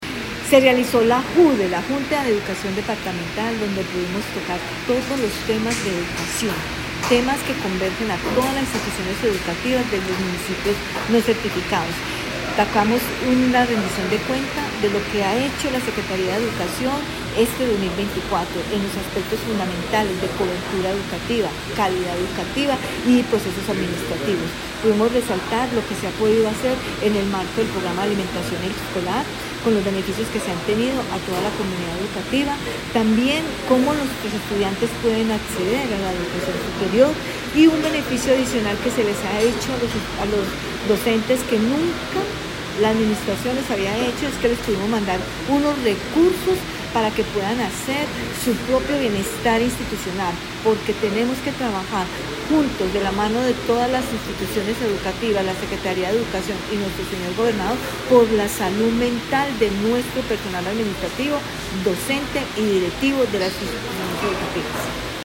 En la reciente Junta Departamental de Educación (JUDE) 2024 se presentó un balance de los logros alcanzados en el sistema educativo de Risaralda, con énfasis en la mejora de la calidad educativa, el bienestar docente y la cobertura.